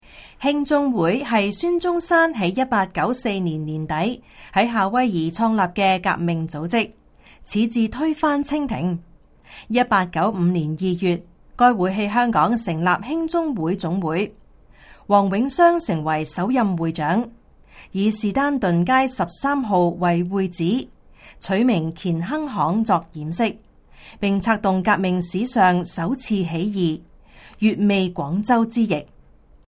語音簡介